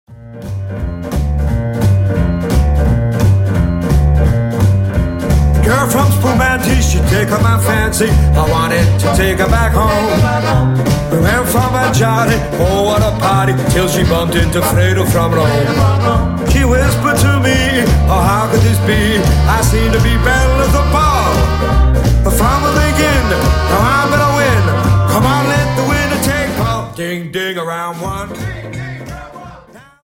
Dance: Jive 42